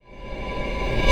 VEC3 FX Reverse 39.wav